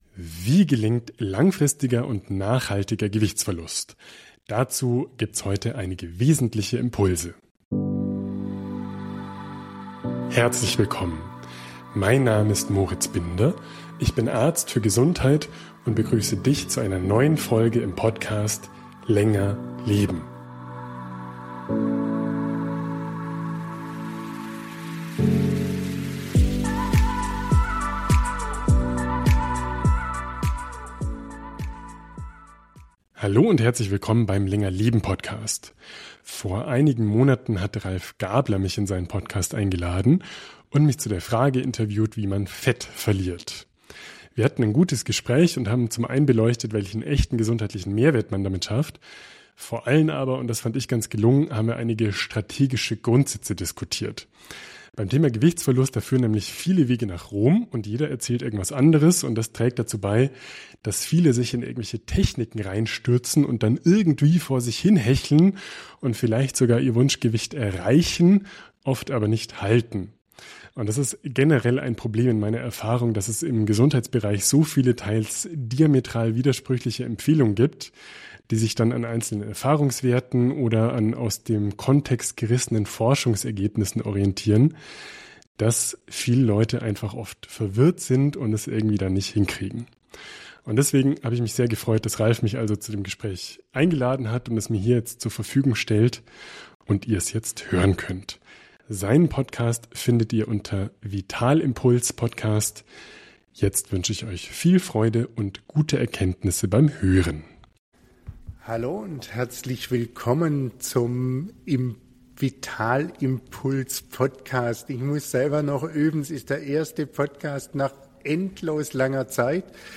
Das Gespräch gibt es jetzt auch als Folge im Länger Leben Podcast.